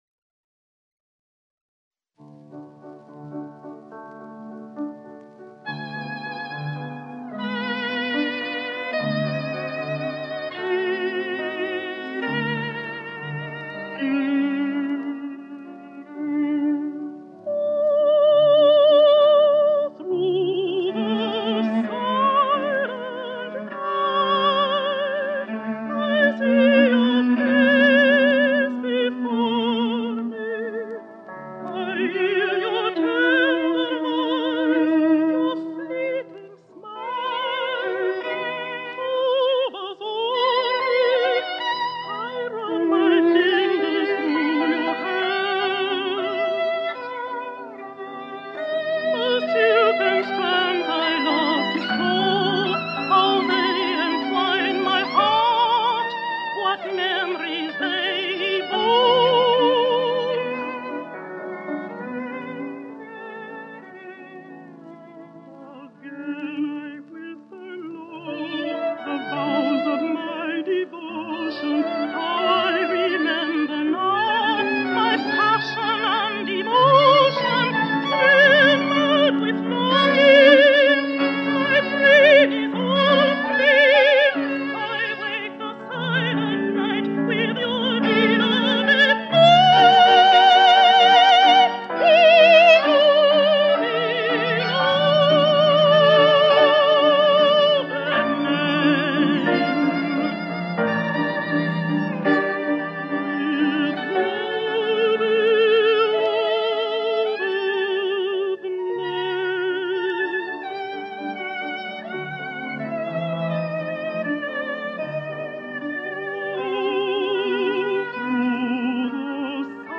"В молчаньи ночи тайной...." Поёт Мариам Андерсон
Сергей Рахманинов. Романс на на стихи Афанасия Фета «В молчаньи ночи тайной». Поет Мариан Андерсон. Альт - Уильям Примроуз, фортепиано - Франц Рупп. Нью-Йорк, 1 июля 1941.